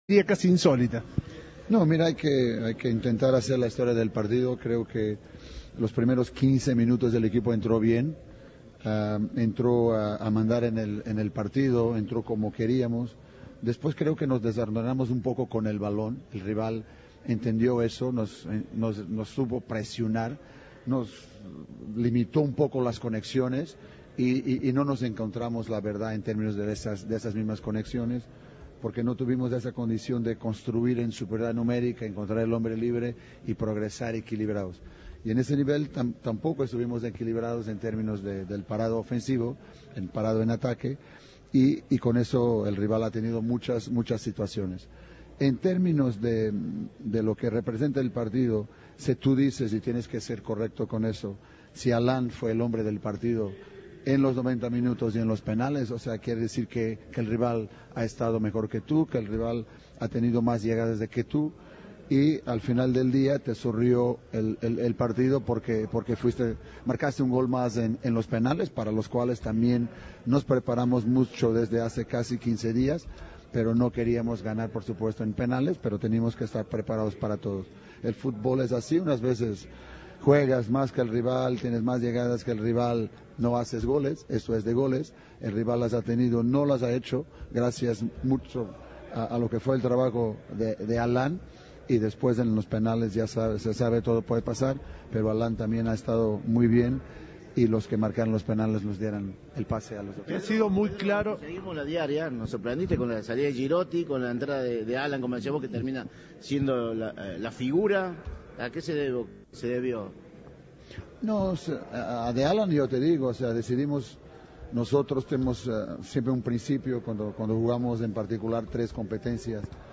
El entrenador de Talleres fue autocrítico con el rendimiento del equipo ante Chaco For Ever tras clasificar a octavos por penales.